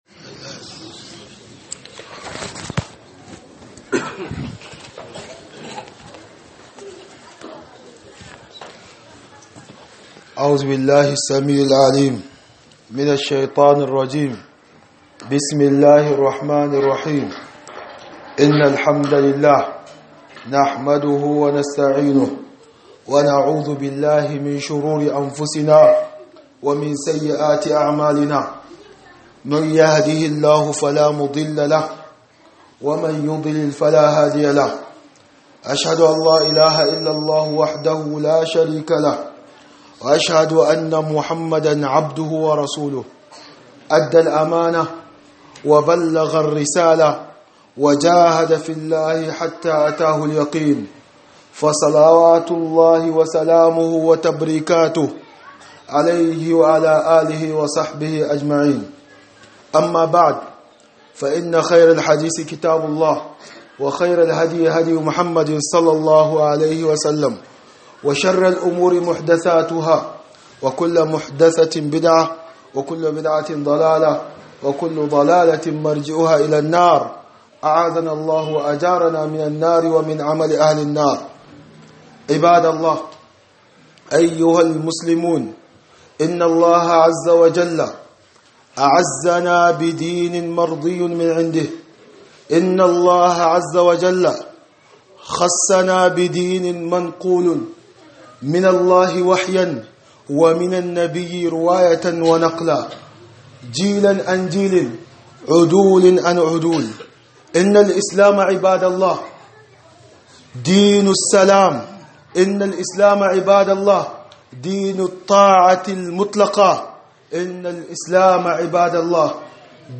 خطبة بعنوان حقيقية الدين الإسلامي